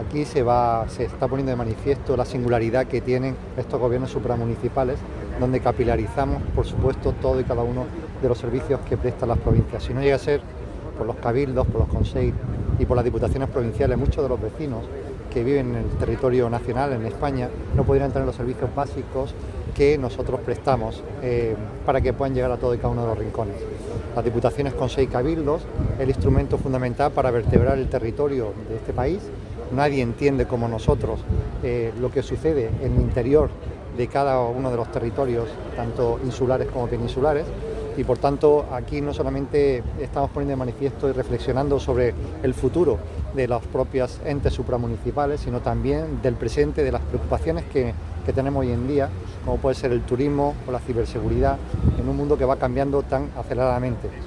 Las 41 diputaciones, siete cabildos y los cuatro consejos insulares se han unido para conseguir un nuevo modelo de financiación local. Así lo ha precisado el presidente de Diputación y de la Comisión de Diputaciones, Consejos y Cabildos de España, Javier A. García, durante la VI Conferencia de Presidentes que está celebrando la Federación Española de Municipios y Provincias en Mallorca.